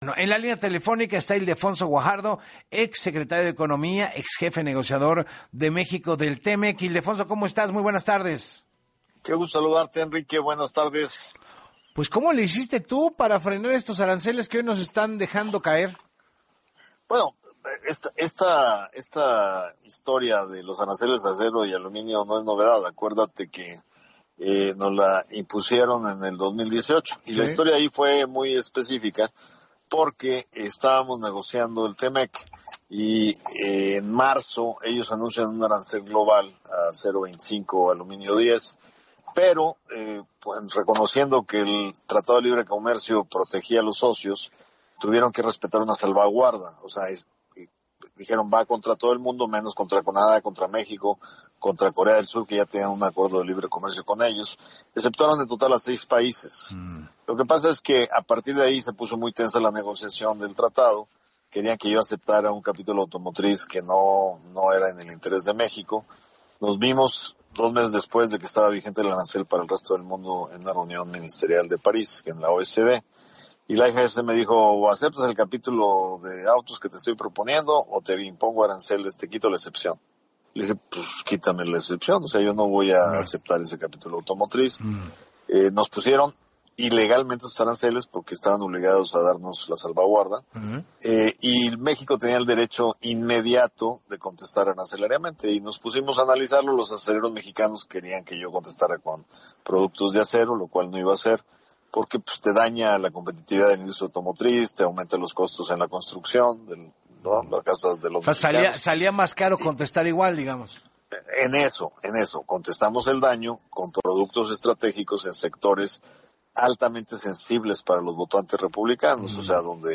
En Así Las Cosas con Enrique Hernández Alcázar, el ex funcionario explicó que en marzo de ese año, Estados Unidos anunció el mismo arancel, pese a la salvaguarda que tenían México y Canadá por el Tratado de Libre Comercio.